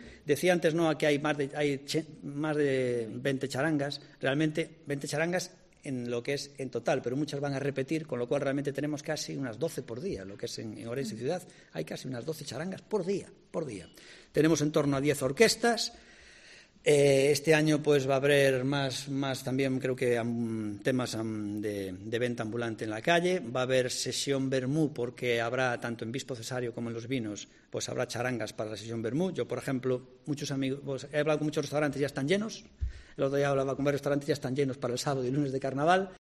El alcalde de Ourense presenta la programación del Entroido